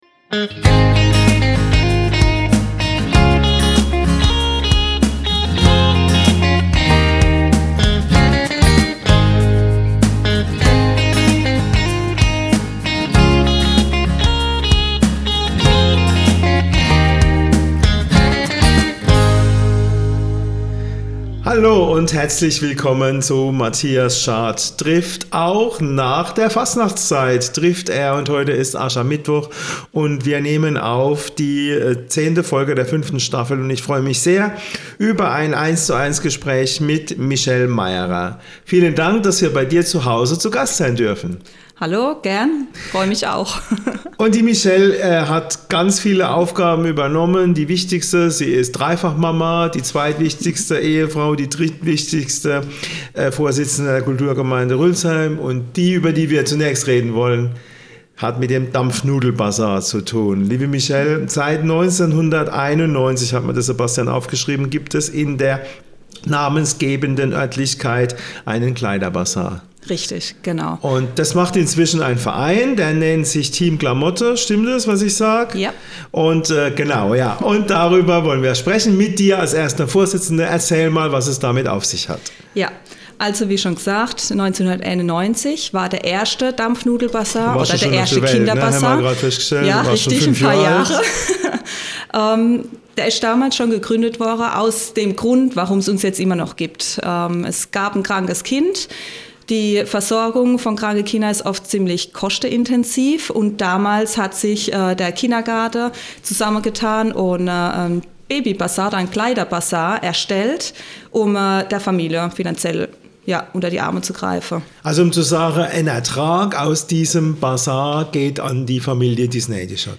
In der zehnten Folge der fünften Staffel trifft Bürgermeister Matthias Schardt zum ersten Mal seit den Anfängen des Formats jemanden zum 1:1-Interview